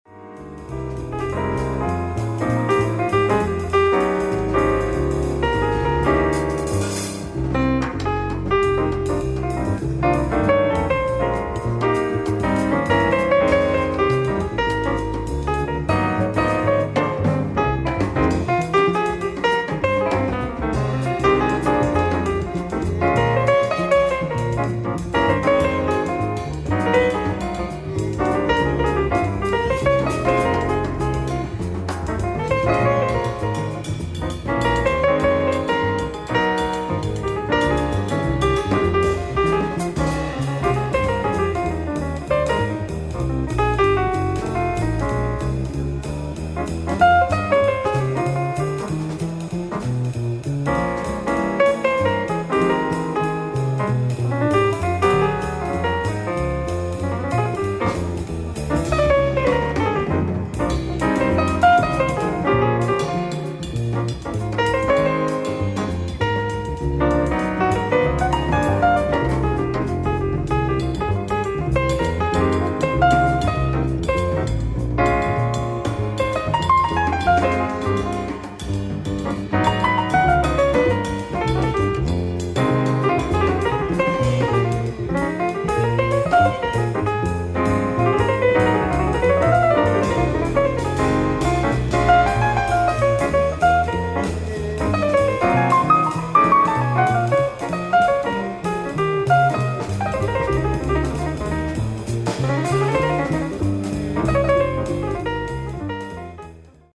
ライブ・アット・ムジークハレ、ハンブルグ、ドイツ 10/18/1989
※試聴用に実際より音質を落としています。